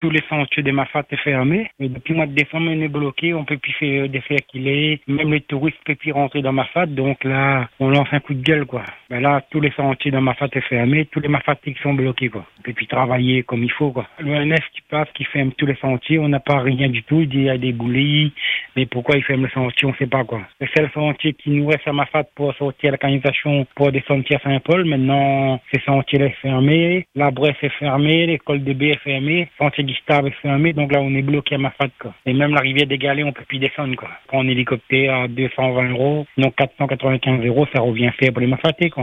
Depuis le mois de décembre, un homme affirme se retrouver bloqué à Mafate, une situation qu’il dit partager avec de nombreux habitants du cirque. Selon son témoignage, plusieurs sentiers auraient été fermés, rendant les déplacements extrêmement compliqués.